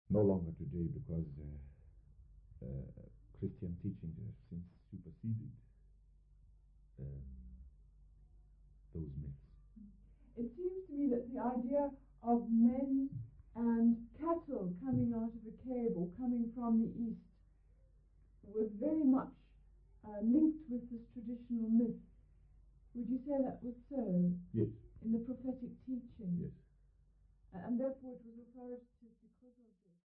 DDC236b-01.mp3 of Interview with Chief Burns Ncamashe (3)